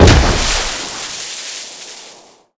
land_on_water_3.wav